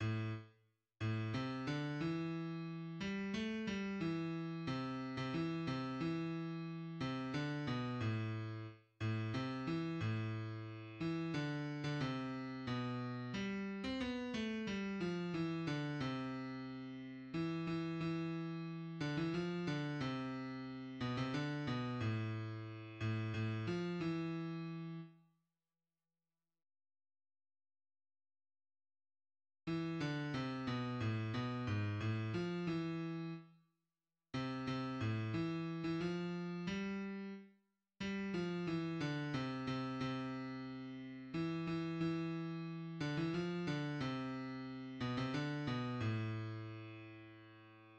{\clef bass \tempo 4=90 \time 3/4 \set Score.currentBarNumber = #1 \bar "" a,8 r4 a,8 c d e4. g8 a g e4 c8. c16 e8 c e4. c8 d b, a,4 r8 a, c e a,4. e8 d8. d16 c4 b, g8. c'16 b8(a g) f e d c2 e8 e e4. (d16 e f8) d c4. (b,16 c d8) b, a,4. a,8 a, f e4. r8 r4 r2. r4 r4 r8 e8 d8 c b, a, b, gis, a, f e4 r4 r8 c8 c a, e8. e16 f4 g r4 g8 f e d c c c2 e8 e e4. (d16 e f8) d c4. (b,16 c d8) b, a,2. }\addlyrics {\set fontSize = #-2 - NIT ZUKH MIKH VU DI MIR- TN GRI- NEN GE- FINST MIKH DOR- TN NIT, MAYN SHATS. VU LE- BNS VEL- KN BAY MA- SHI- NEN, DOR- TN IZ- MAYN RU- E PLATS. DOR- TN IZ MAYN RU- E PLATS MAYN RU- E PLATS. GE- FINST MIKH DOR- TN NIT, MAYN SHATS MAYN SHATS A SHKLAF VU KEY- TN KLIN- GEN DOR- TN IZ- MAYN RU- E PLATS DOR- TN IZ MAYN RU- E PLATS } \midi{}